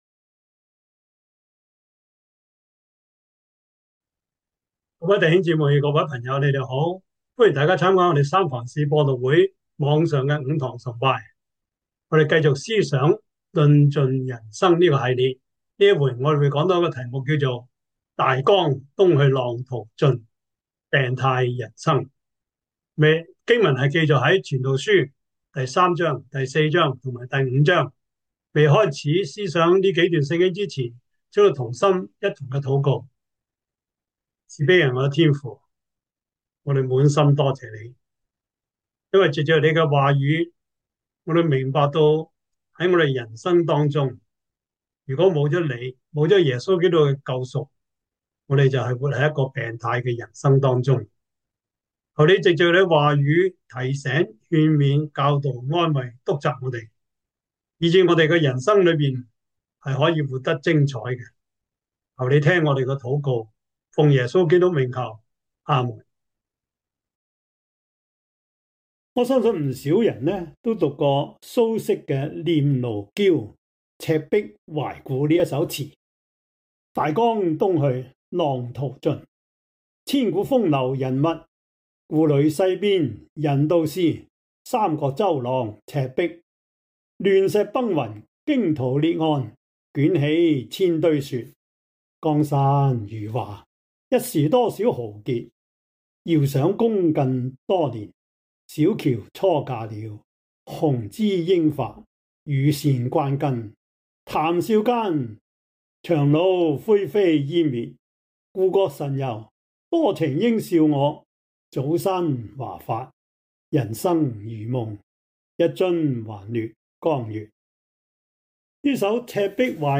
傳道書 4:1-12 Service Type: 主日崇拜 傳道書 4:1-12 Chinese Union Version